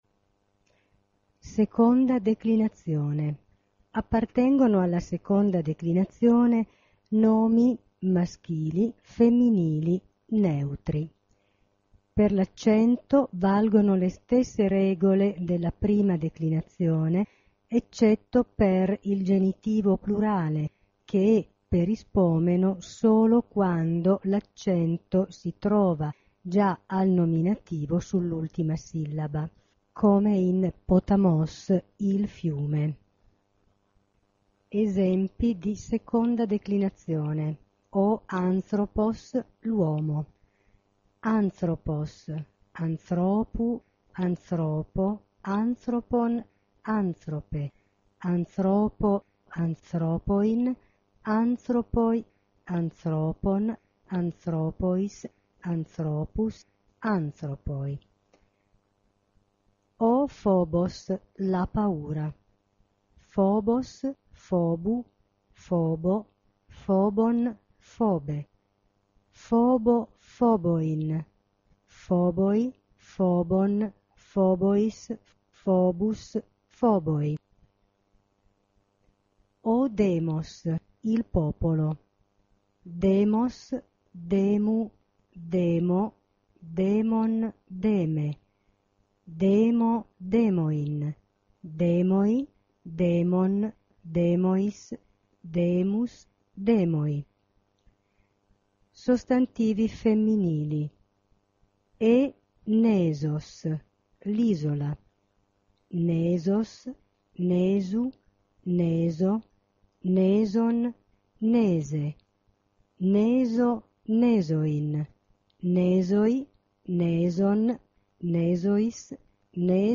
seconda declinazione) permette di sentire la lettura dei nomi maschili e femminili della seconda declinazione.